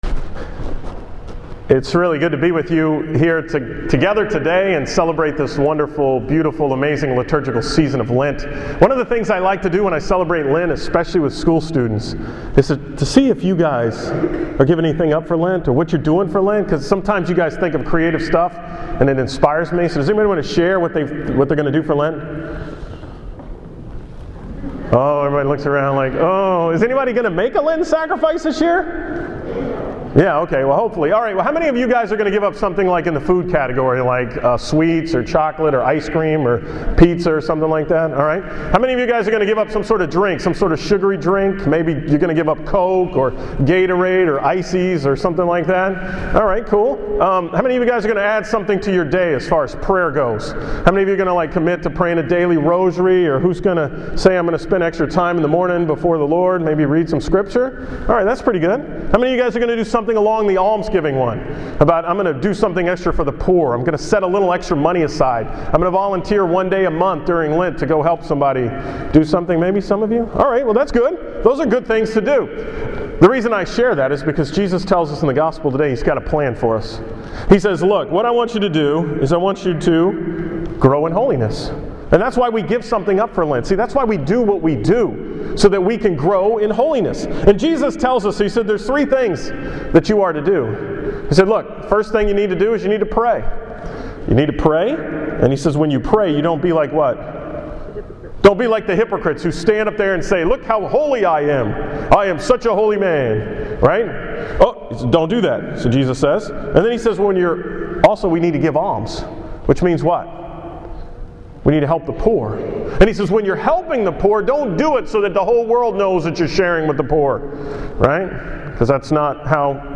From the 9 am Mass at St. Patrick's Church in Galveston